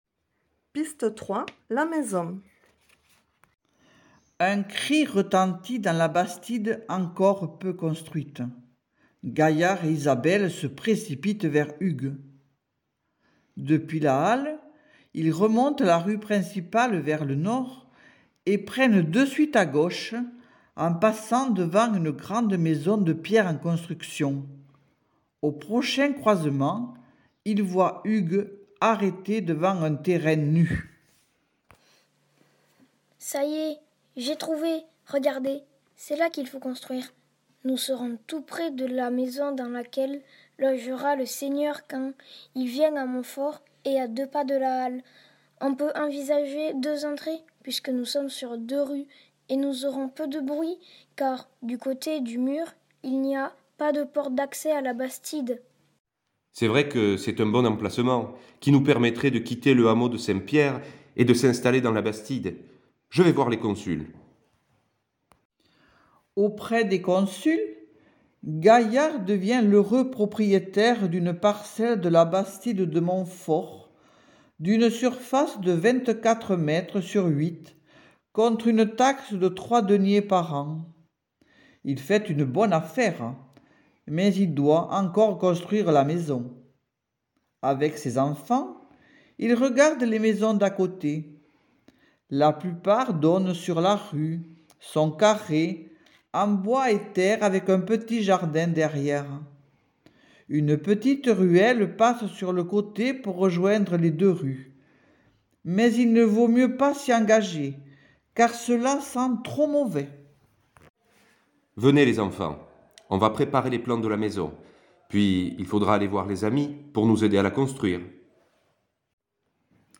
Une visite ludique en audio!
Venez découvrir les secrets de la fondation d'une bastide en écoutant les habitants de Monfort vous raconter cette aventure comme si vous remontiez le temps à l'époque du Moyen-Age!